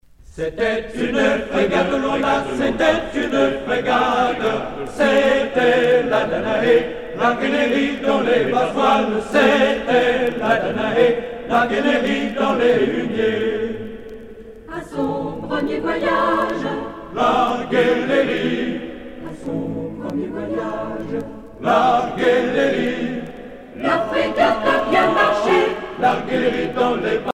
Genre laisse